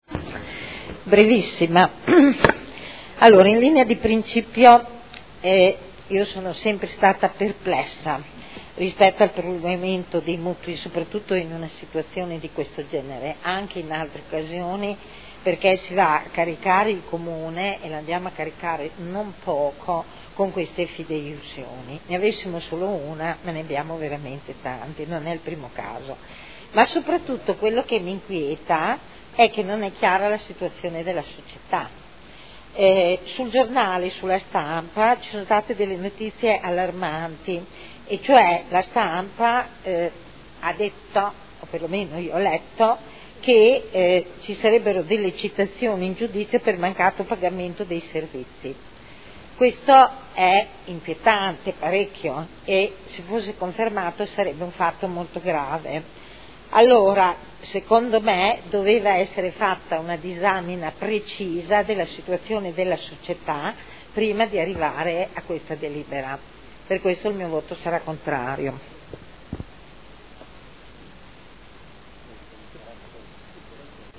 Eugenia Rossi — Sito Audio Consiglio Comunale
Proposta di deliberazione: Proroga garanzie fideiussorie a favore dell’Istituto per il Credito Sportivo per i mutui negoziati da parte della Società Modena Footbal Club. Dichiarazioni di voto